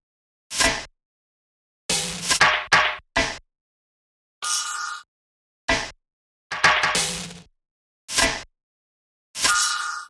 Tag: 95 bpm Electronic Loops Drum Loops 1.70 MB wav Key : Unknown